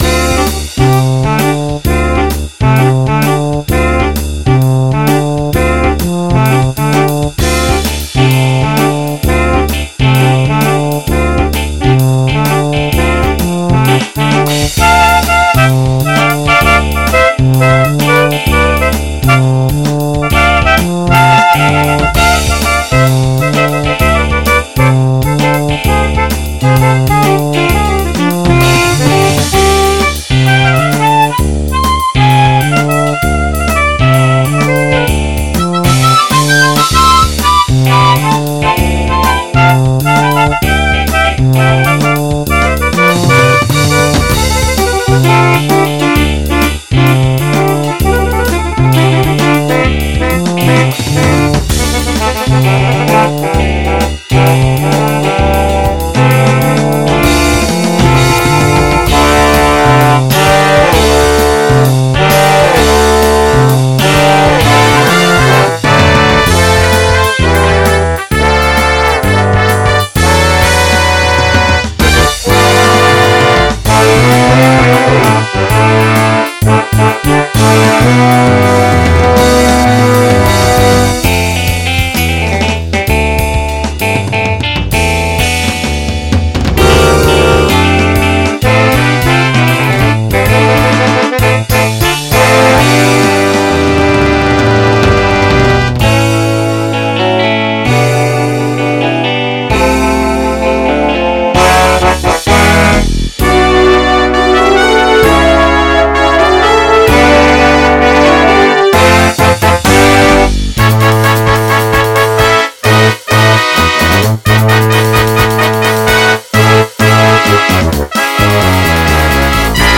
MP3 (Converted)
guitar
bass
horn section
drums